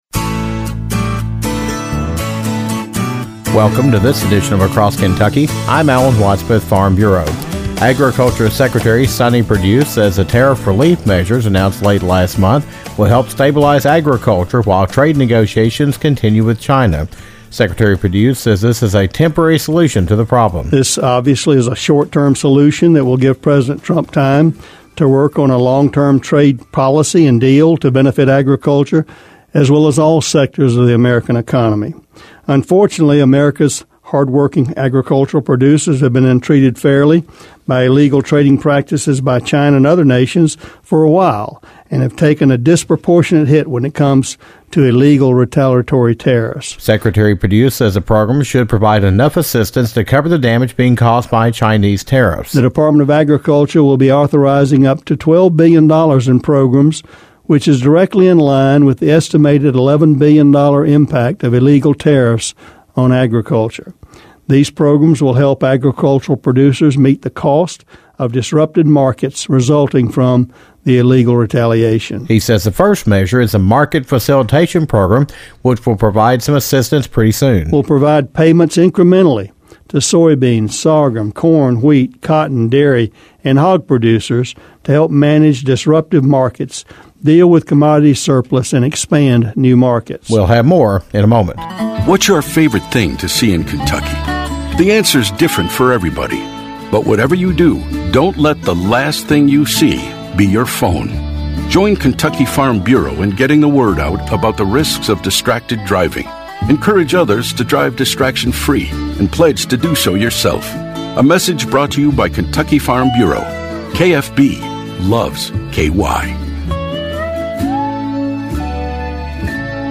The United States Department of Agriculture will providing assistance to farmers affected by the tariffs that are resulting from the Chinese trade war.  Secretary of Agriculture Sonny Perdue discusses the tariff relief measures and what will be implemented to help farmers deal with the loss of income, a surplus of agriculture products and ways to look for new markets.